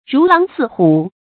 rú láng sì hǔ
如狼似虎发音
成语注音 ㄖㄨˊ ㄌㄤˊ ㄙㄧˋ ㄏㄨˇ